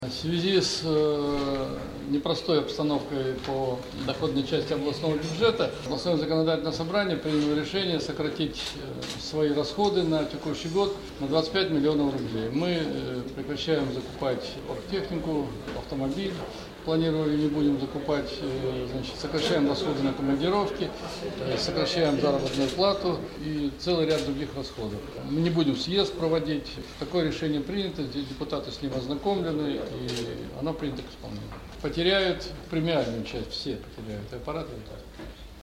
Георгий Шевцов рассказывает об уменьшении зарплат в ЗСО
63084_shevtsov.mp3